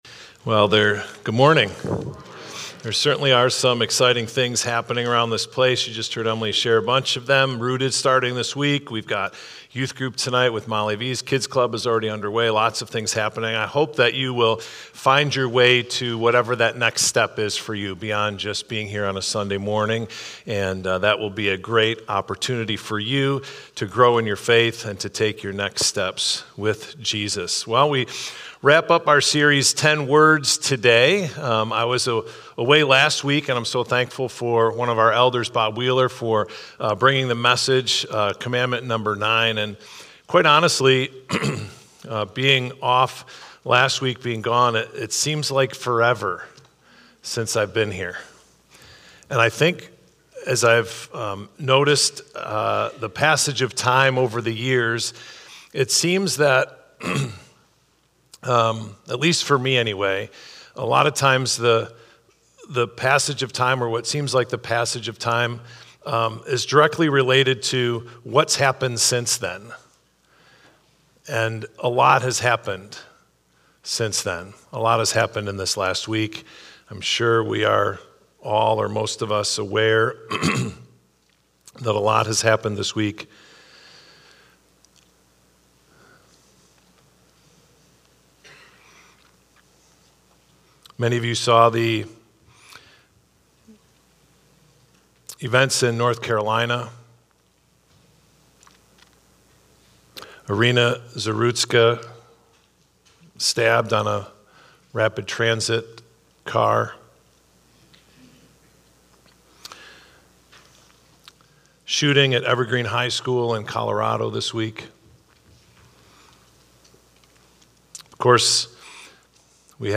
Thank you for joining us this morning! Our church is teaching on a series of Ten Words: a study of the ten commandants.